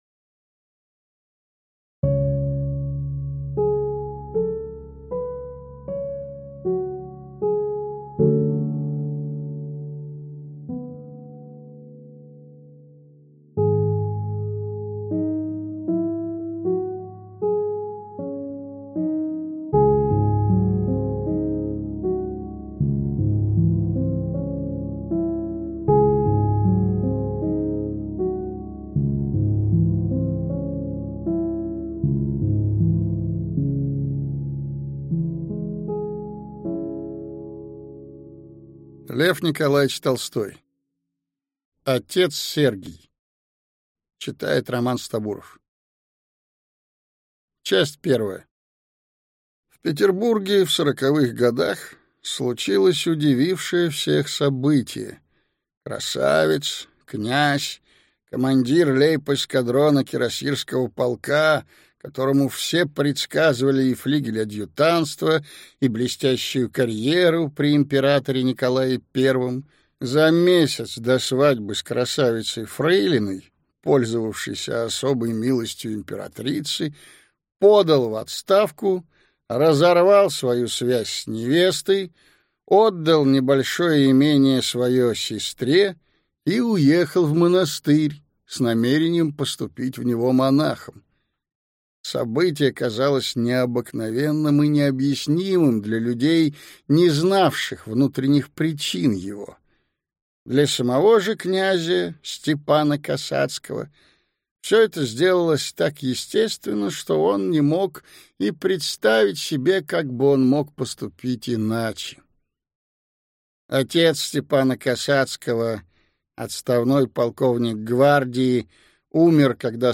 Аудиокнига Отец Сергий | Библиотека аудиокниг
Прослушать и бесплатно скачать фрагмент аудиокниги